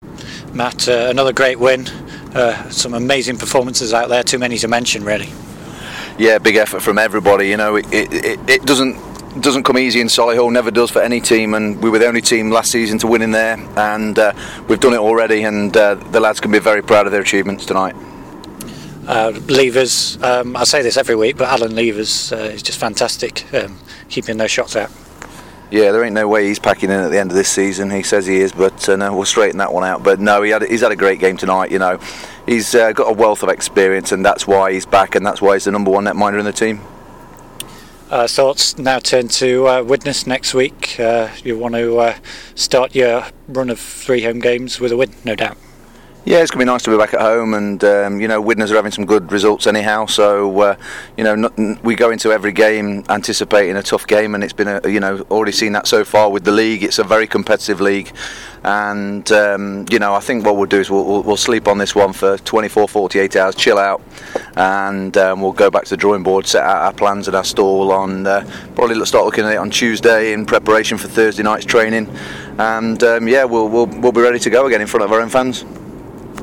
Post-game interview